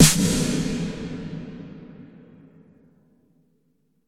Snare - Roland TR 48